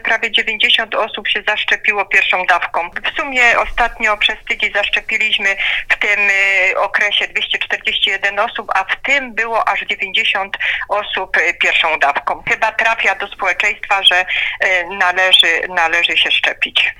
Jak mówi Radiu RDN Małopolska wiceprezydent Tarnowa Dorota Krakowska, wzrost zakażeń jest mocno niepokojący.